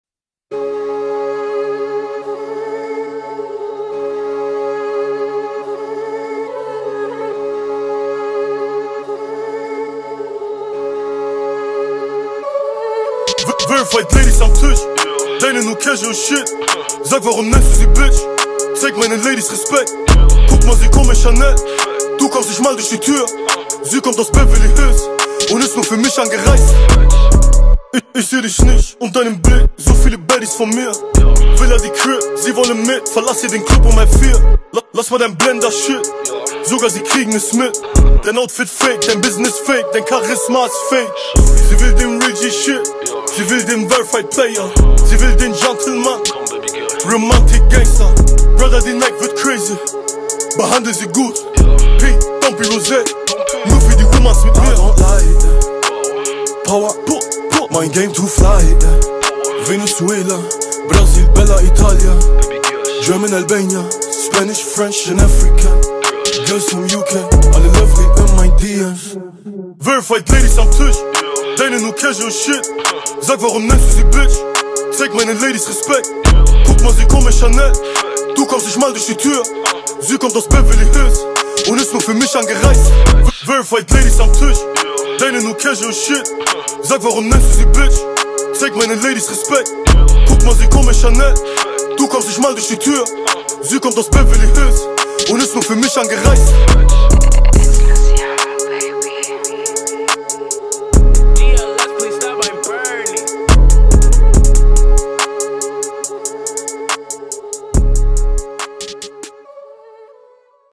Talented and German rap sensation and songwriter